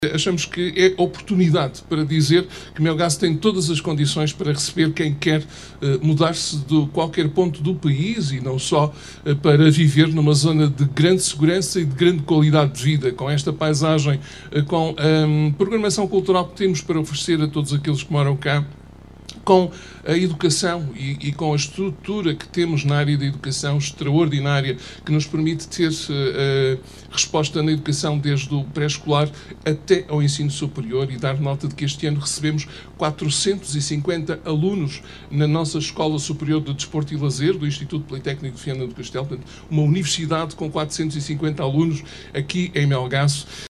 Em direto, no programa Aqui Portugal da RTP, Manoel Batista não perdeu mais uma oportunidade para enviar recados ao Governo.
Ainda durante a emissão deste sábado, Manoel Batista voltou a convidar – agora em direto – todos os portugueses a Viver em Melgaço. É o nome da campanha iniciada recentemente pelo Município onde são apresentadas várias razões, imateriais e económicas, do porquê escolher Melgaço para viver.